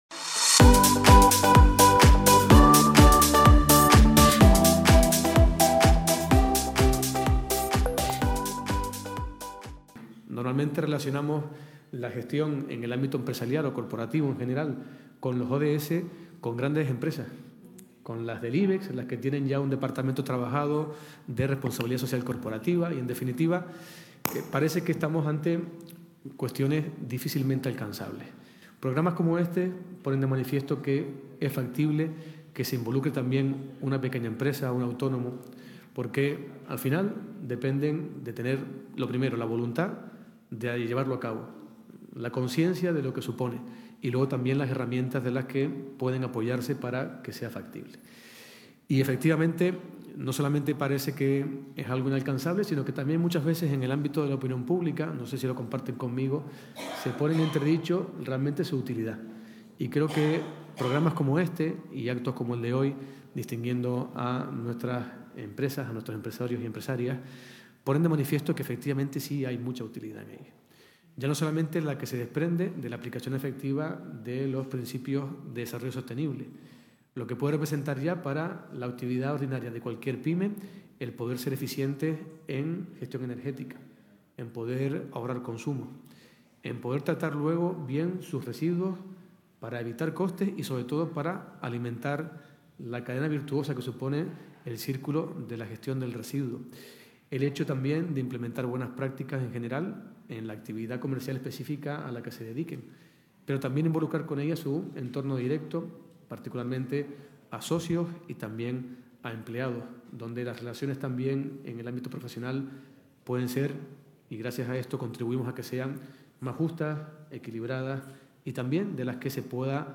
El Salón Noble del Cabildo acogió hoy (miércoles 12) la clausura del Proyecto “ODS En Mi Empresa”, una iniciativa impulsada por la institución insular en colaboración con la Federación de Áreas Urbanas de Canarias (FAUCA).